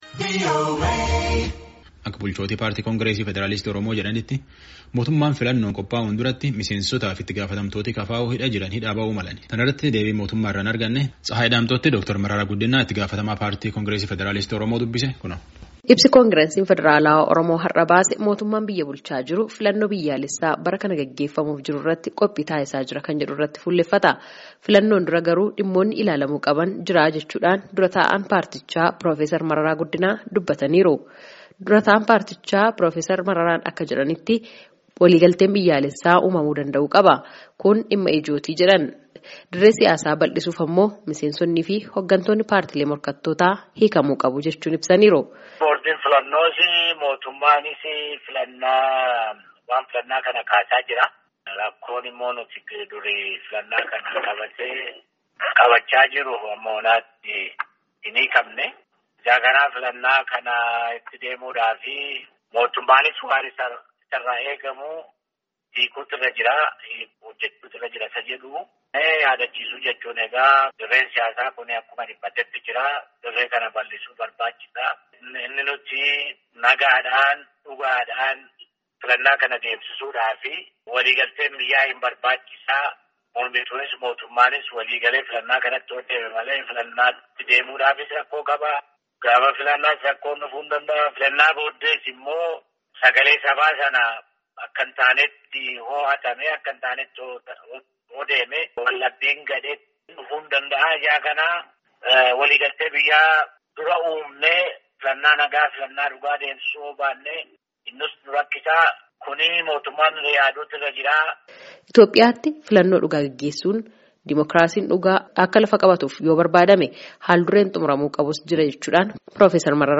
Dura ta'aan paartichaa piroofesar Mararaa Guddinaa Raadiyoo Sagalee Ameerikaatti akka himanitti dirree siyaasaa bal'isuun diimookiraasiin dhugaa Itoophiyaatti akka dhalatuuf mootummaan hidhamtoota paartilee siyaasaa morkattootaa hiikuu qaba.
Gabaasaa guutuu caqasaa.